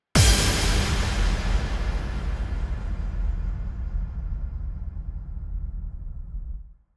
SouthSide Stomp (2) .wav